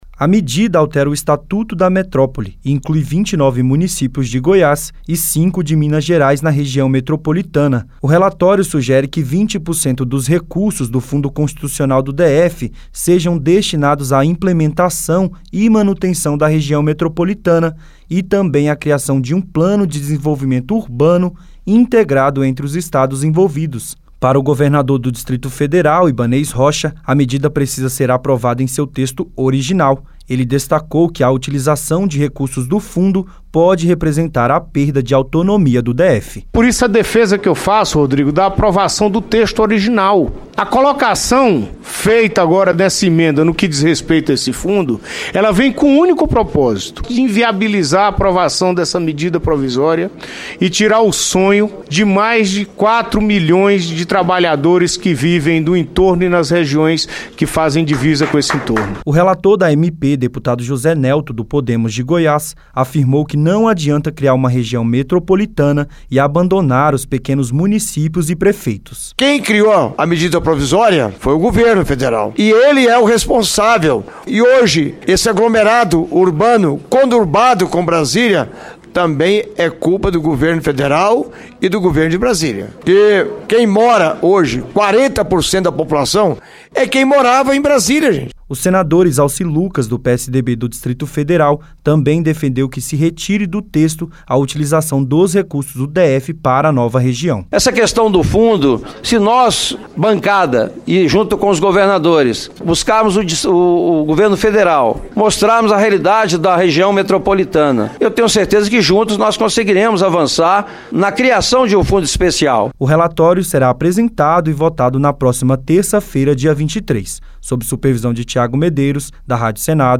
O senador Izalci Lucas (PSDB – DF) defende que se retire do texto a utilização dos recursos do DF para a nova região. A reportagem